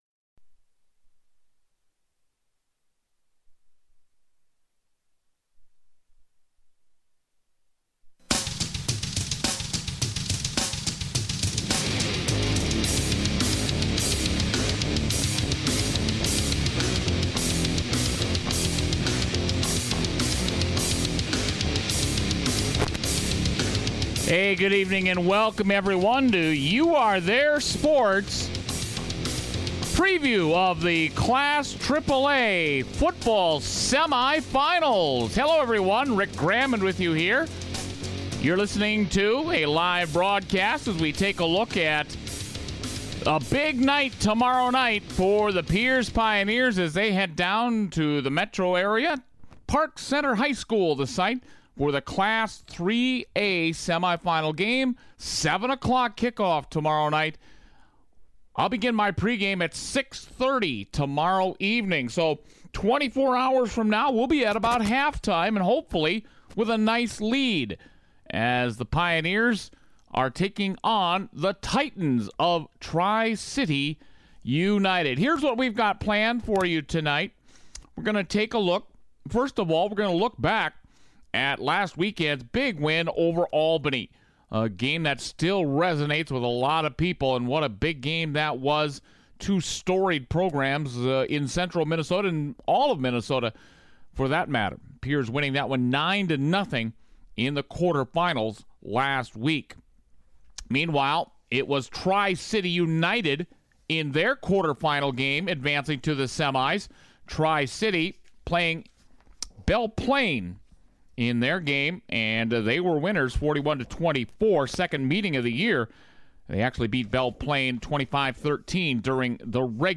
A look back at the Albany win, interviews with Coaches and a look at the entire State Tournament.